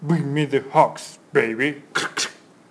press_start_2.wav